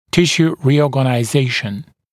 [‘tɪʃuː rɪˌɔːgənaɪ’zeɪʃn] [-sjuː][‘тишу: риˌо:гэнай’зэйшн] [-сйу:]реорганизация тканей